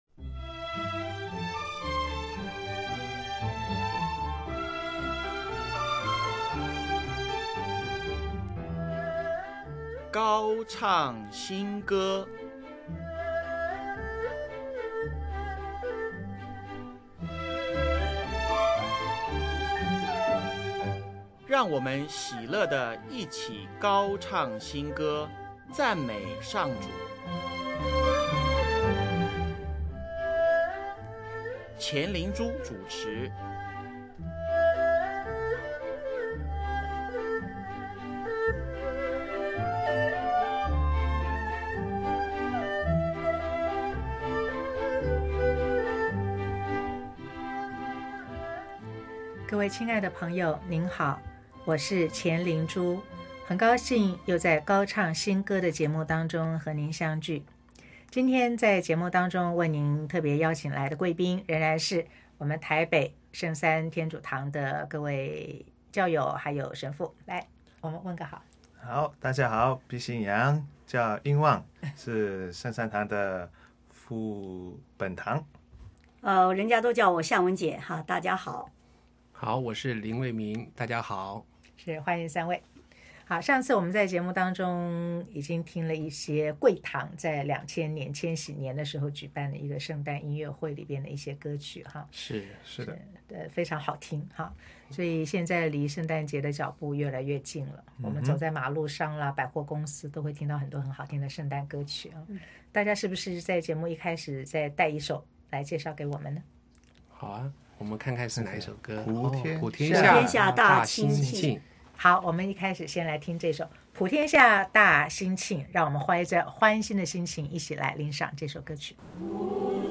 【高唱新歌】140|圣诞分享及礼讚(二)：用竹子演奏圣歌
教堂中八个孩子一个负责一个音阶，用竹子演奏圣歌，神父花了很大心思教导孩子，孩子也花了很多时间练习。